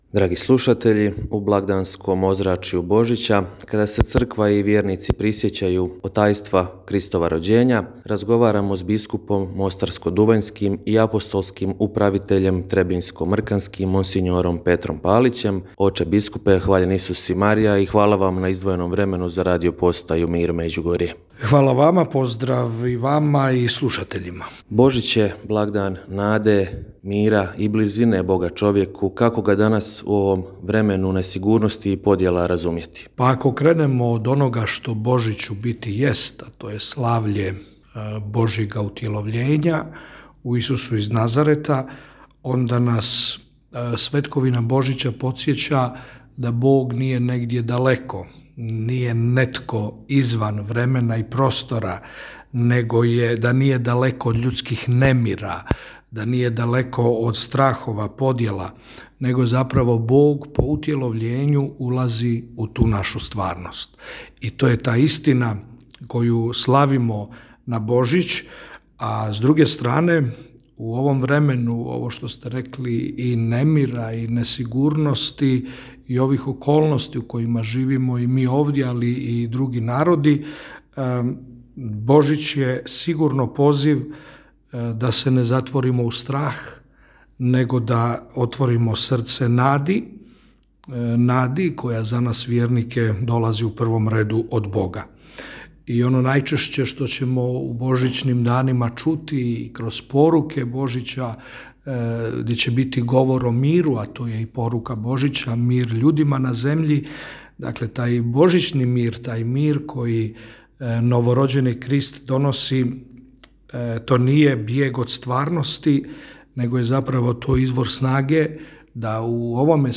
U blagdanskom ozračju Božića, kada se Crkva i vjernici prisjećaju otajstva Kristova rođenja, gost u programu našega radija bio je biskup mostarsko-duvanjski i apostolski upravitelj trebinjsko-mrkanski, mons. Petar Palić.